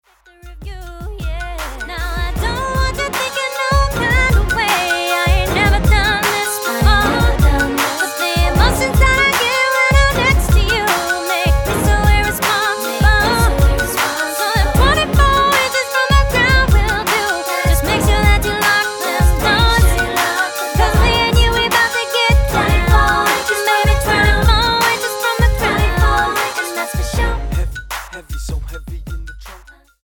NOTE: Background Tracks 10 Thru 18